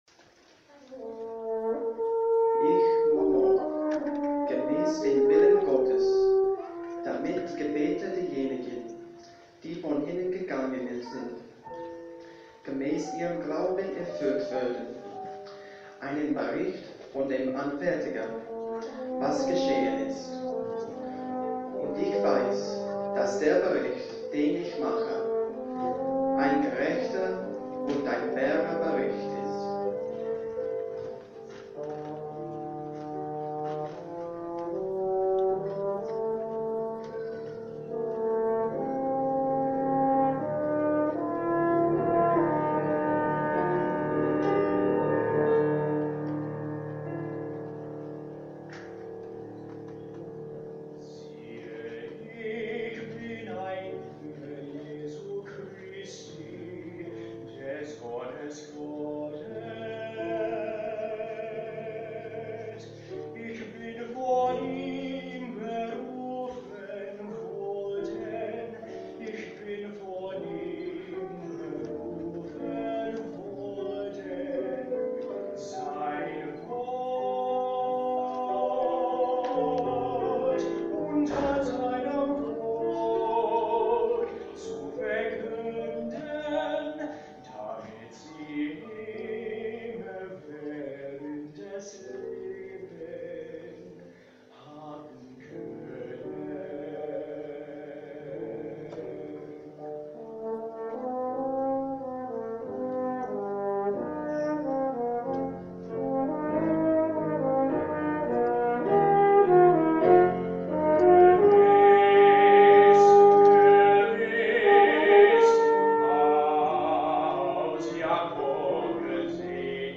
Baritone, Horn, and Piano